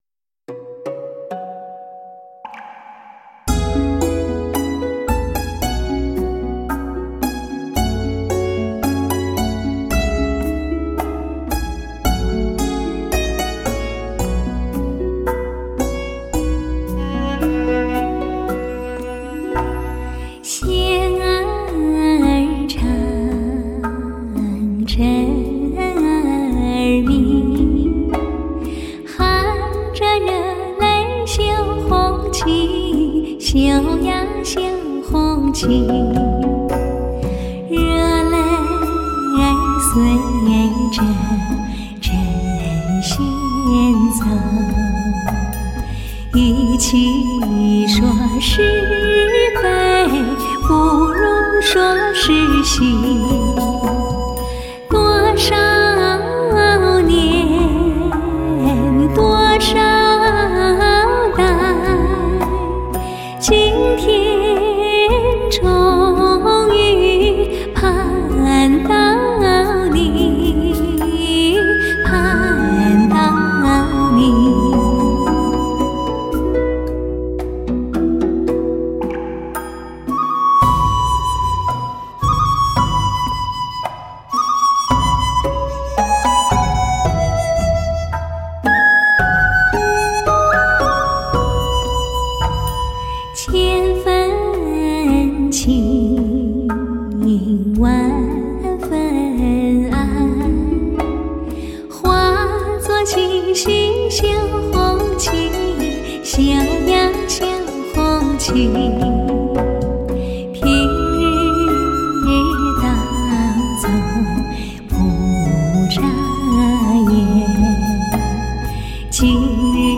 优美的歌曲  伴随着我们  回忆美好的时光